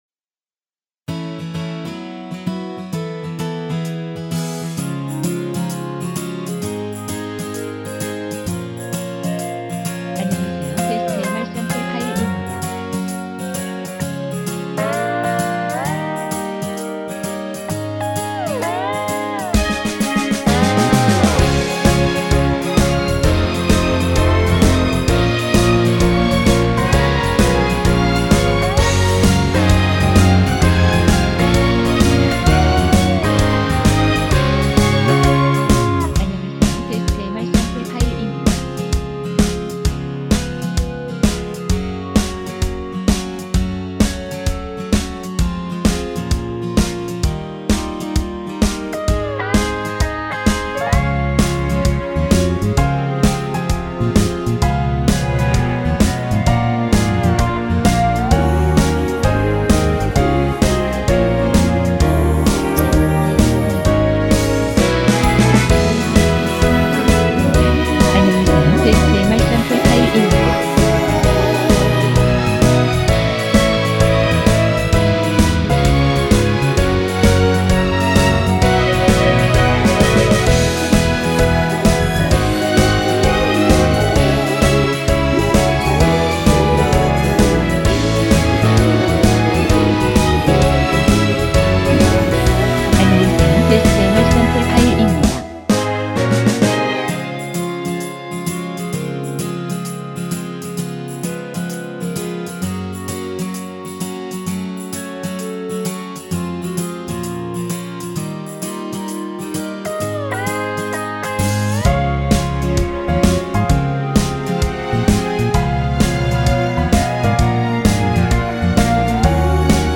원키 멜로디와 코러스 포함된 MR이며 전체 미리듣기 가능하니 확인후 이용하세요.
전주 없는 곡이라 전주 2마디 만들어 놓았습니다.
엔딩이 페이드 아웃이라 라이브 하시기 편하게 엔딩을 만들어 놓았습니다.
Db
앞부분30초, 뒷부분30초씩 편집해서 올려 드리고 있습니다.